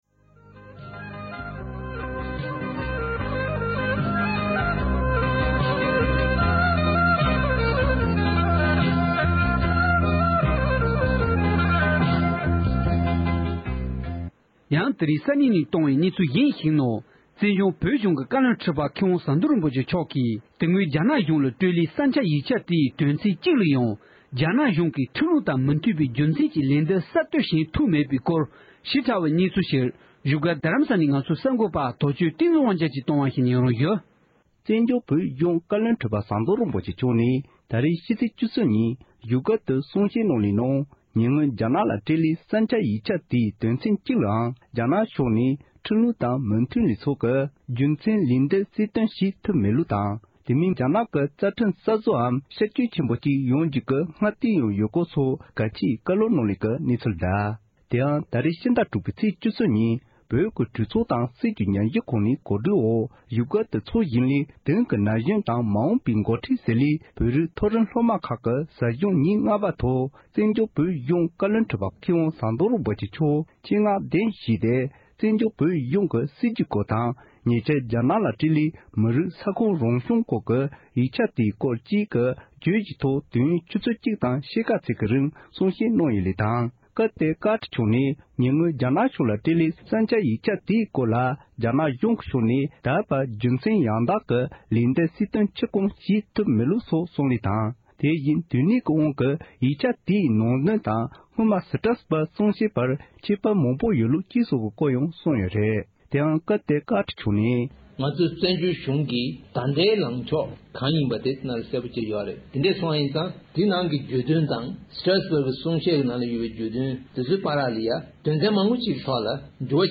ཟབ་སྦྱོང་ཐོག་གསུང་བཤད།
བཀའ་བློན་ཁྲི་པ་མཆོག་གིས་རྒྱ་ནག་ཏུ་ཕུལ་བའི་བསམ་འཆར་ཡིག་ཆ་དེའི་སྐོར་གསུང་བཤད་གནང་བ།
སྒྲ་ལྡན་གསར་འགྱུར།